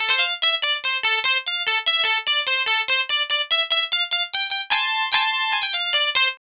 Twisting 2Nite 5 Clav-F.wav